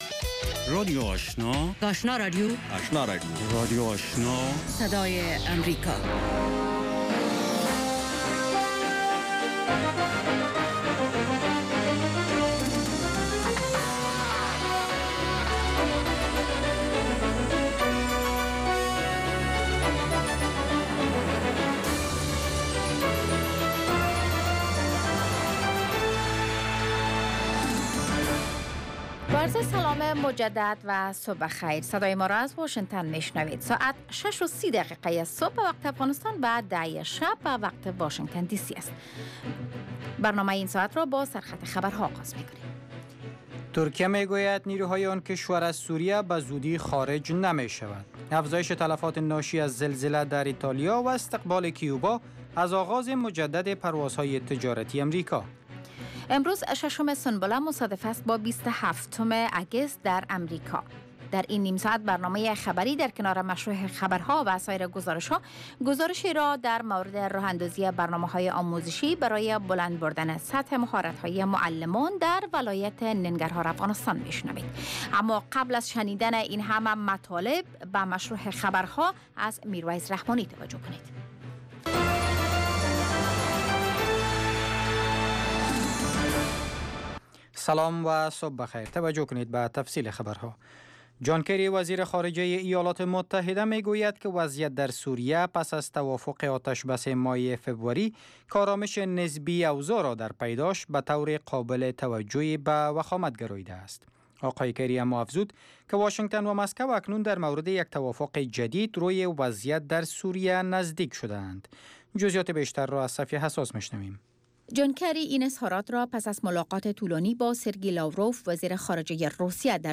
دومین برنامه خبری صبح